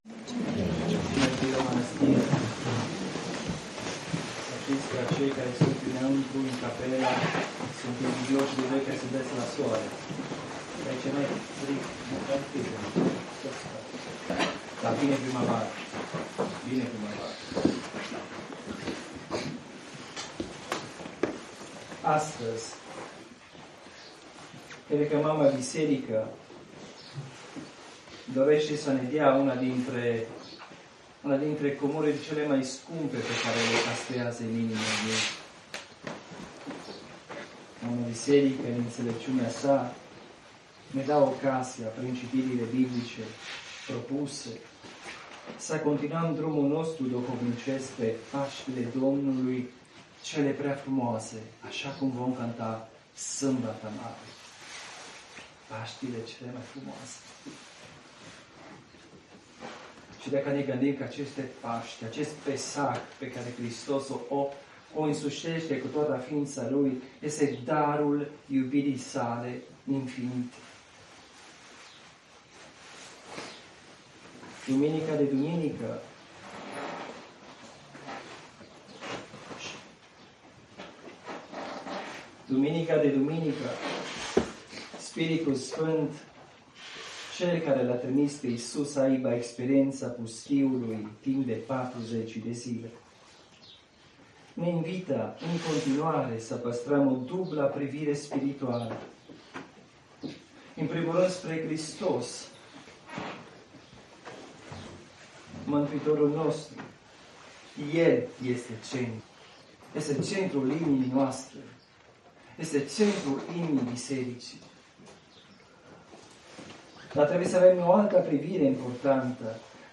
Predica din Duminica sf Ioan Scararul